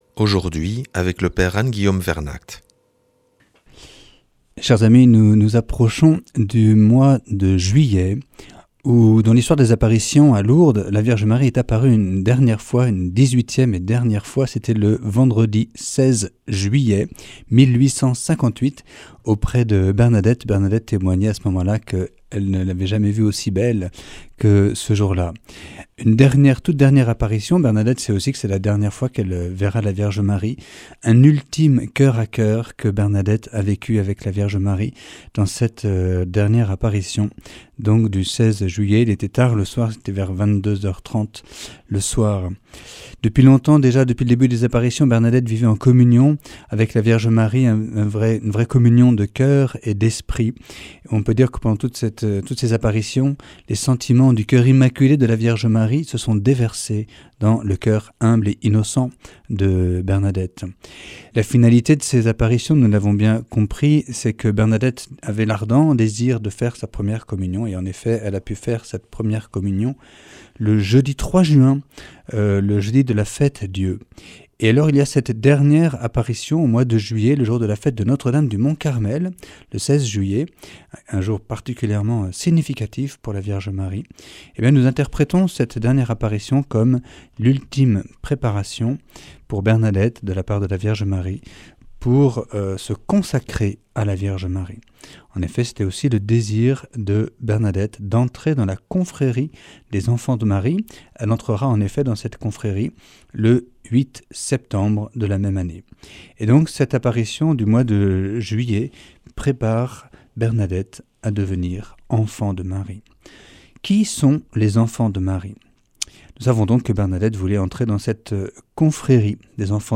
lundi 24 juin 2024 Enseignement Marial Durée 10 min